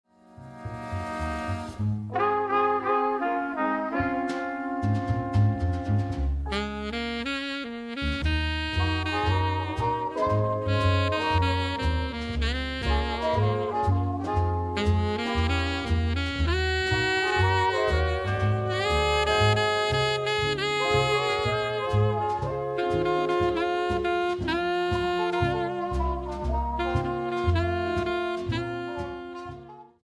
Saxophone Alto